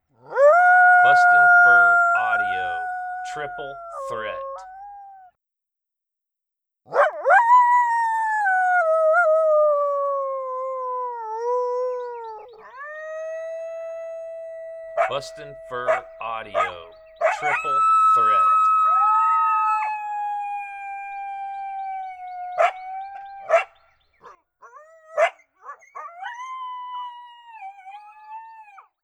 Apollo initiates a 3 way howl with Beans and their pup from last year, Cash. The boys throw monotone lone howls while momma beans does her usual thing, gets loud!
• Product Code: howls